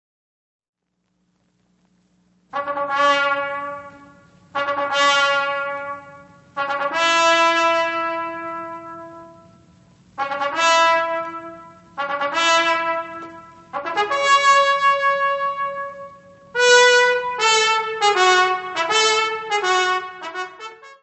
: mono; 12 cm + folheto (12 p.)
Music Category/Genre:  Classical Music